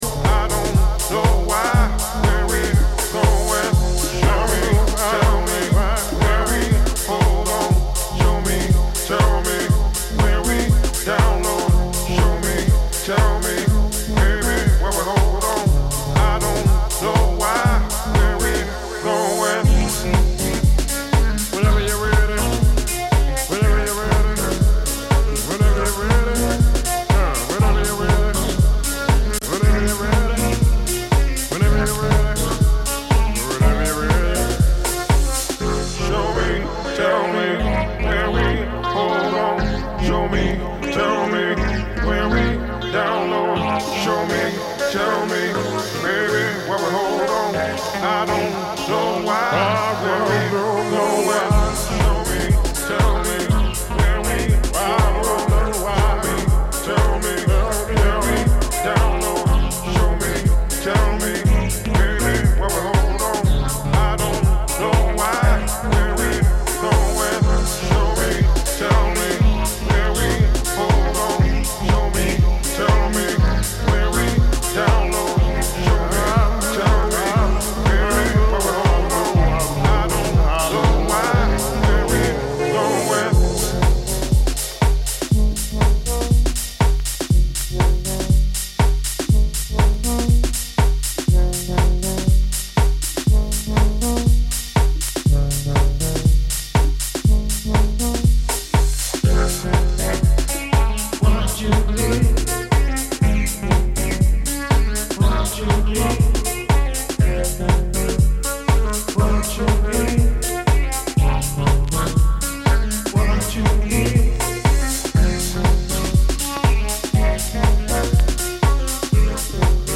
House Dein Browser kann kein HTML5-Audio.